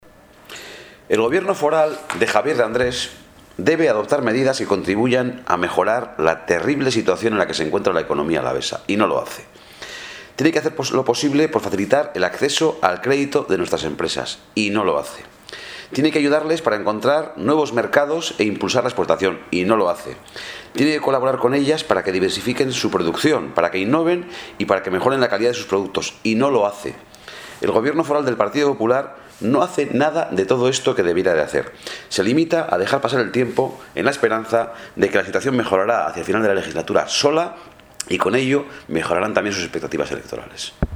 Juntas Generales Araba: Ramiro Gonzalez denuncia la falta de políticas económicas de la DFA